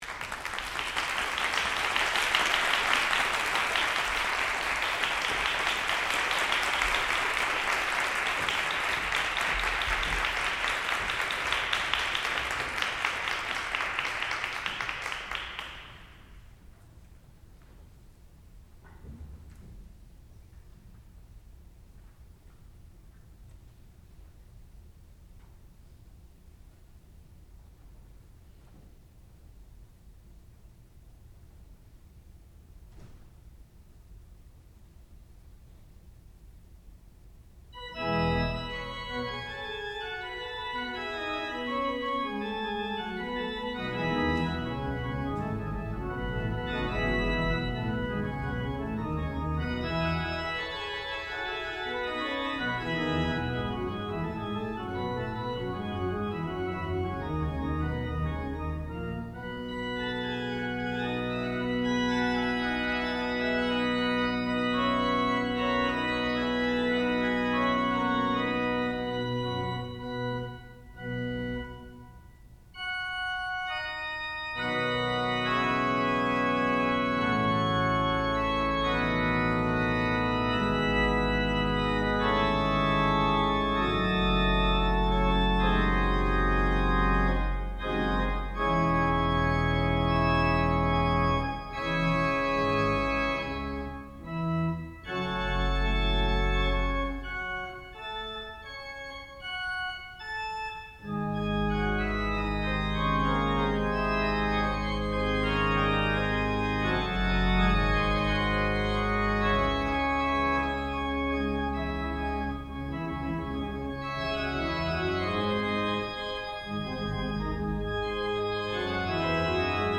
sound recording-musical
classical music
organ